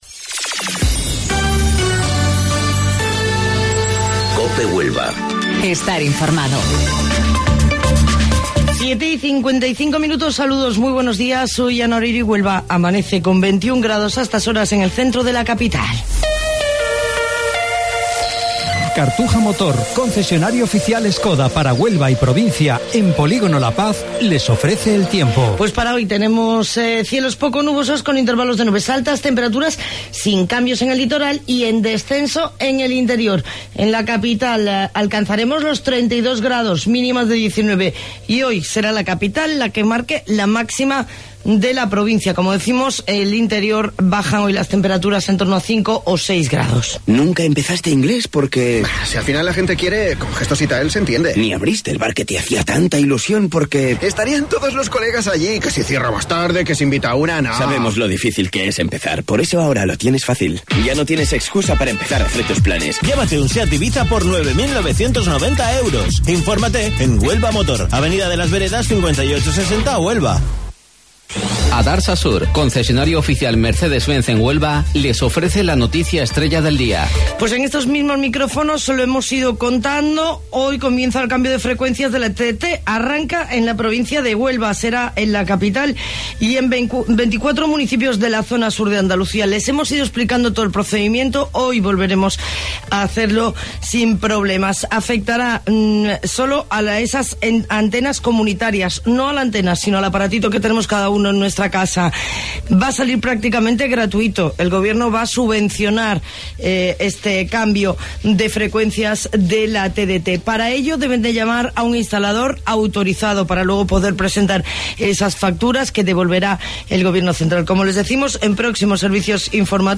AUDIO: Informativo Local 07:55 del 26 de Julio